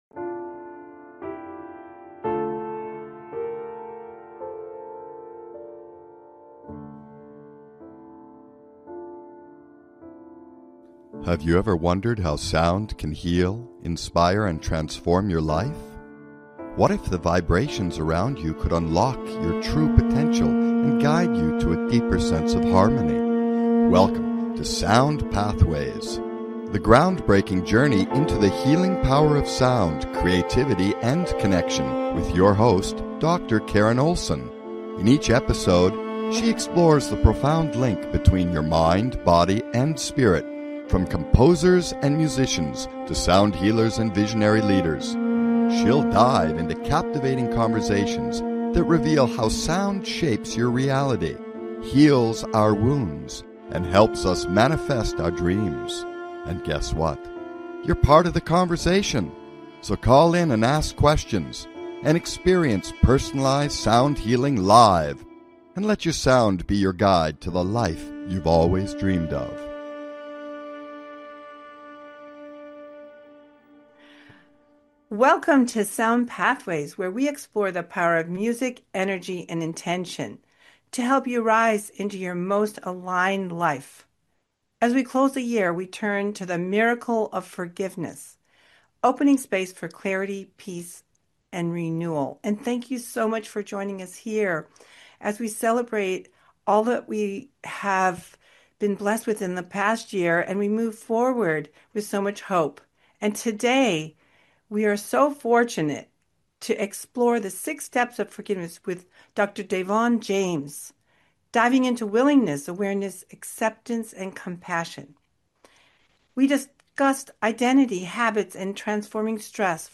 Talk Show Episode
We’ll close with an intuitive viola sound bath crafted to help you let go, harmonize, and rise.